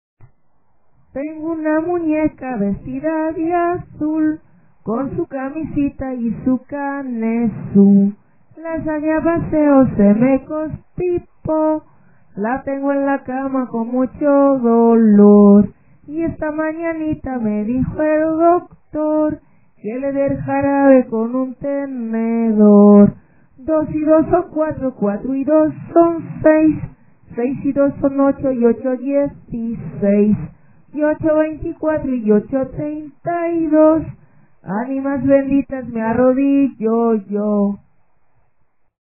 La mélodie est la même que "Brinca la tablita"
C'est la version chantée en Espagne.